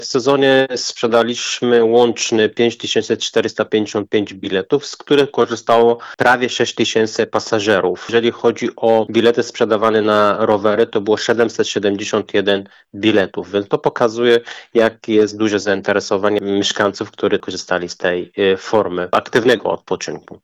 O przedsięwzięciu, które okazało się strzałem w dziesiątkę, mówi starosta policki Shivan Fate: